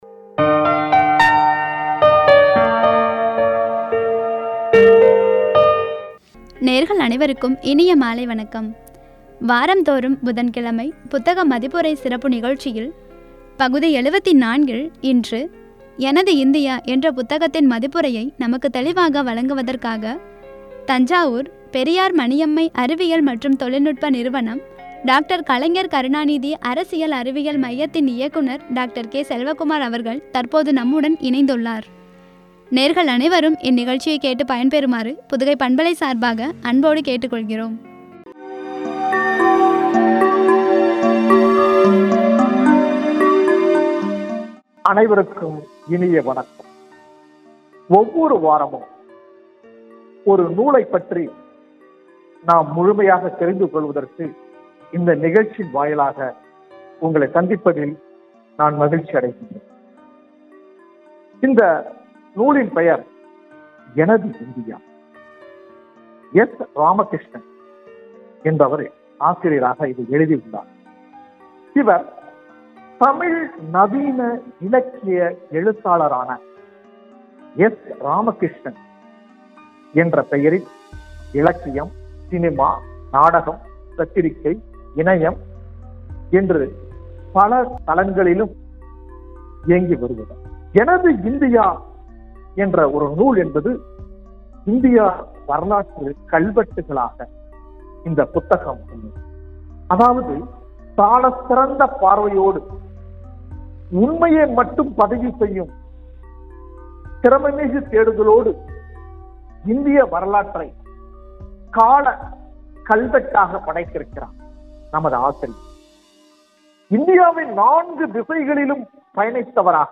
வழங்கிய உரை.